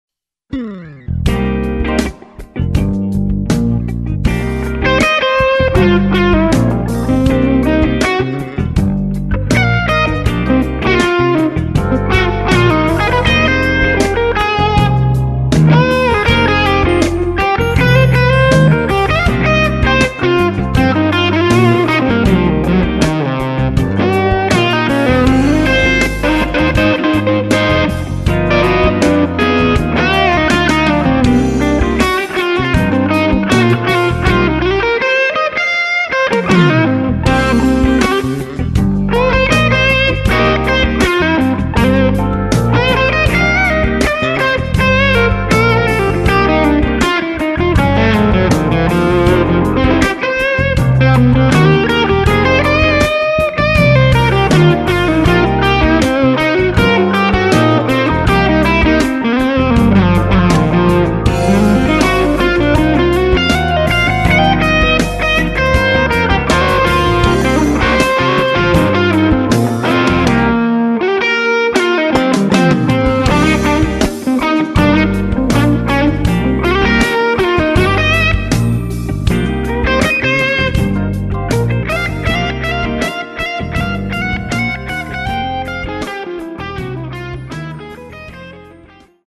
SM57 shoved to G1265. No tweaking mic positions, turning amp knobs, first take.......
A little IR Verb added during mixdown.
Amp is Music Man high plate Skyliner with 6L6 tubes